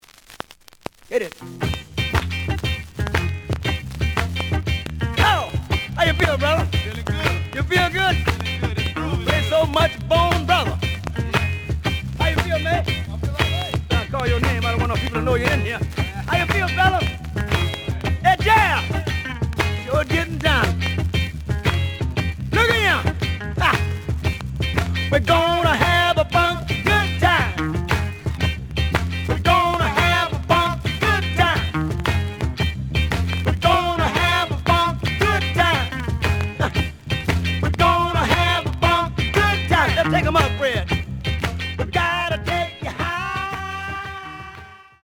The audio sample is recorded from the actual item.
●Genre: Funk, 70's Funk
Some noise on B side.)